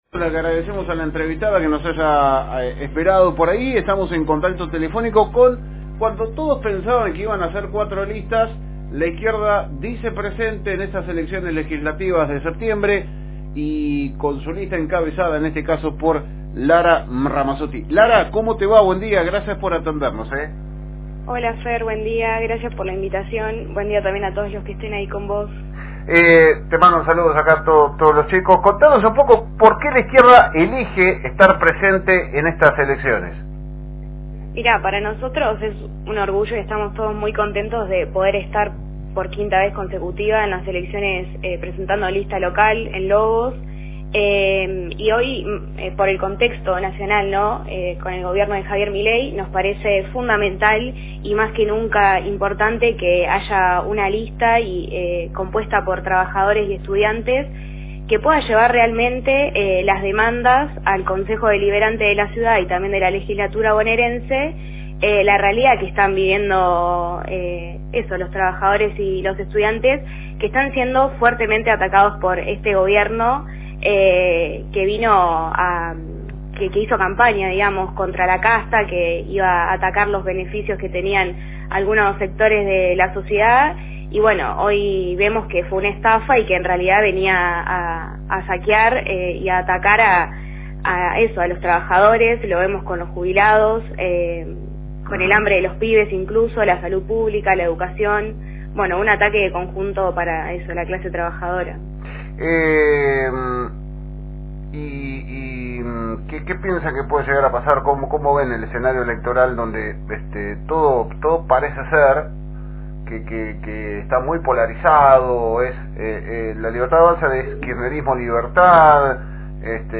Conversamos con ella en Esto es Noticia para conocer un poco cuales son las propuestas y objetivos del partido de izquierda para lograr ingresar al concejo.